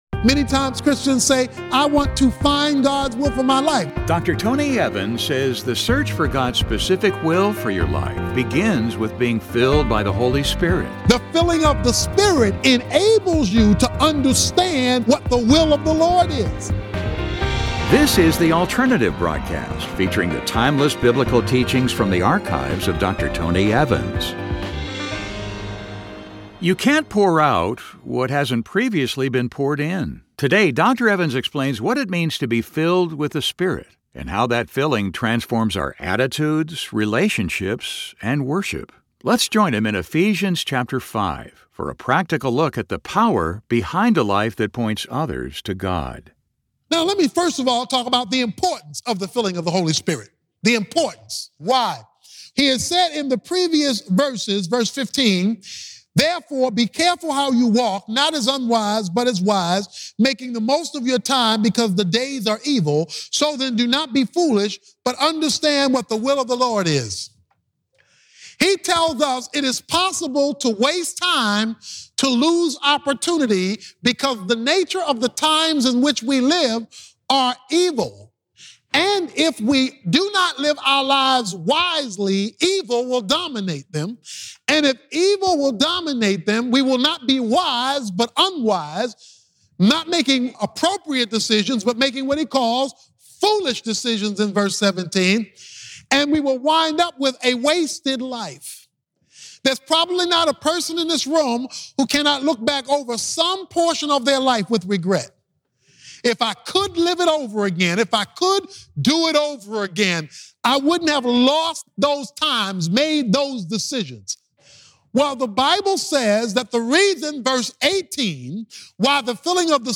In this message, Dr. Tony Evans explains what it means to be filled with the Spirit and how that filling transforms our attitudes, relationshipsÂ and worship.